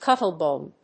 アクセント・音節cúttle・bòne